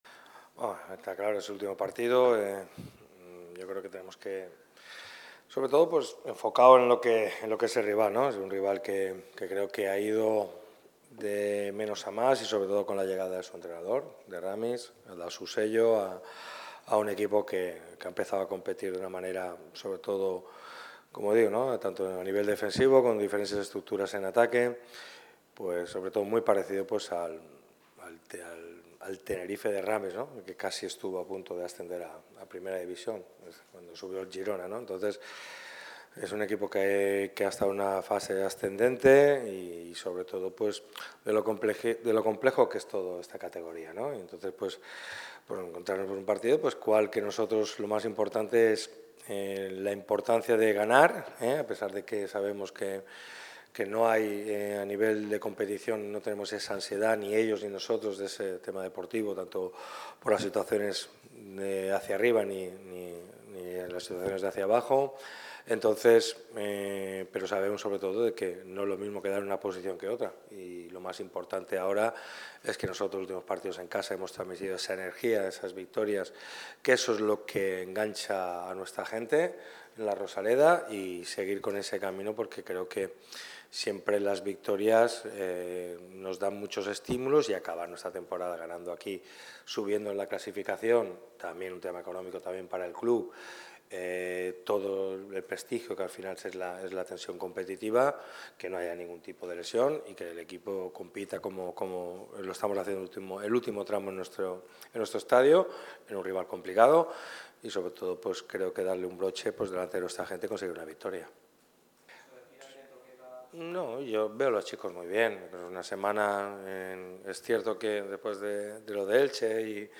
El entrenador del Málaga CF ha comparecido ante los medios en la previa del duelo que enfrentará a los boquerones contra el Burgos CF mañana sábado a las 18:30 horas en La Rosaleda. El técnico ha repasado diversos temas de importancia en clave Málaga CF como la última jornada que afrontan sus pupilos, el estado de la plantilla y repasa nombres propios.